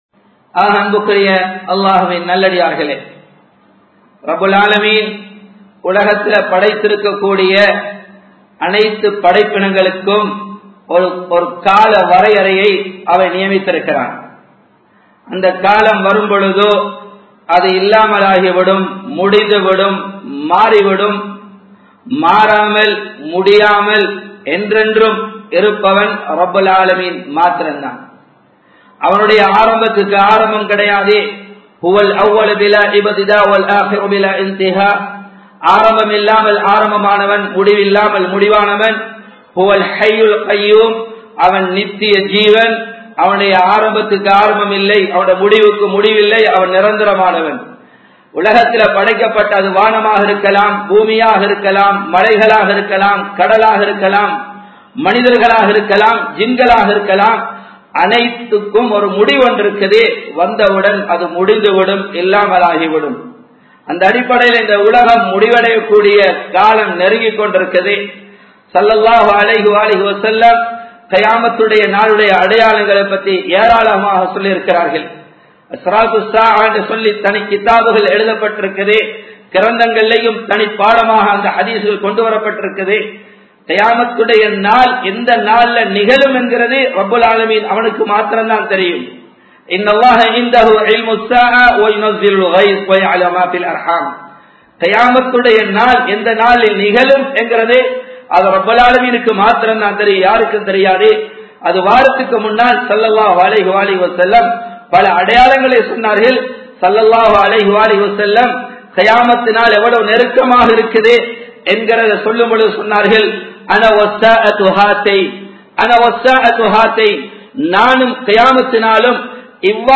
கியாமத் நாளின் அடையாளங்கள் | Audio Bayans | All Ceylon Muslim Youth Community | Addalaichenai
Colombo 11, Samman Kottu Jumua Masjith (Red Masjith)